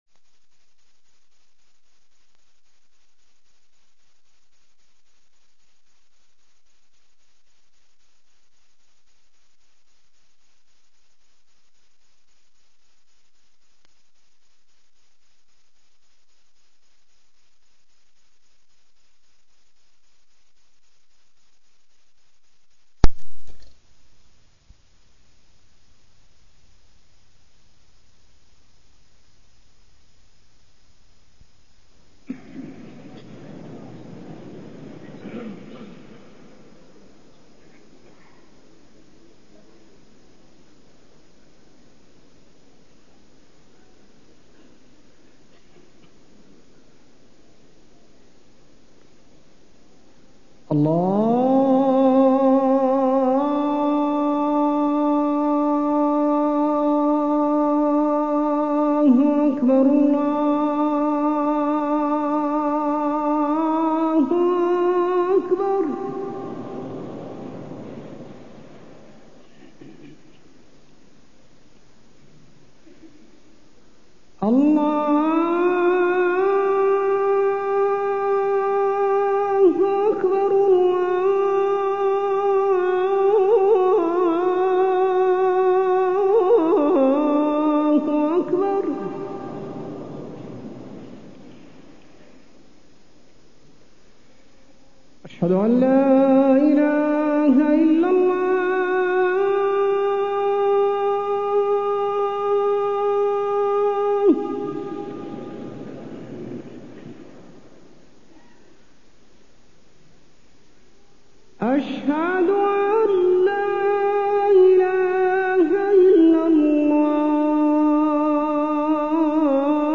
تاريخ النشر ٣ ربيع الثاني ١٤٢٣ هـ المكان: المسجد النبوي الشيخ: فضيلة الشيخ د. حسين بن عبدالعزيز آل الشيخ فضيلة الشيخ د. حسين بن عبدالعزيز آل الشيخ الرشوة